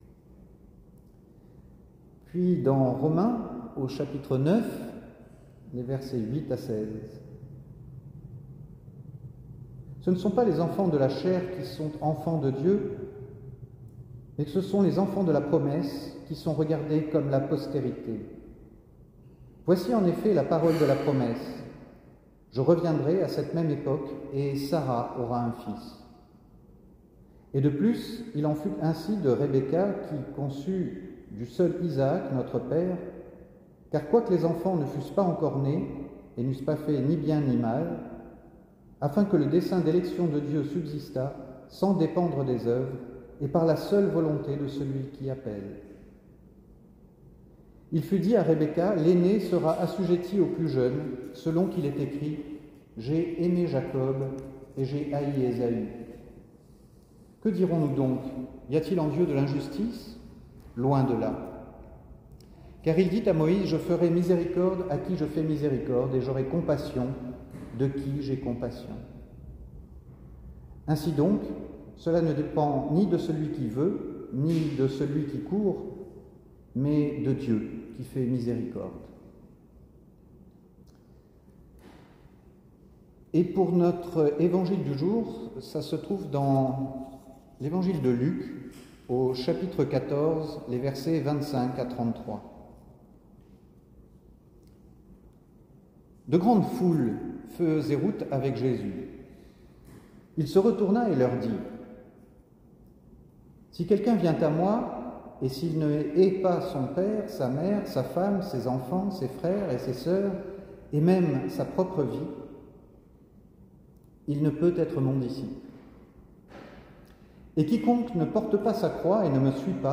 Culte du dimanche 4 septembre 2022
Enregistrement audio de la prédication
le dimanche 4 septembre 2022 à Londres sur l’évangile de Luc 14:25-33.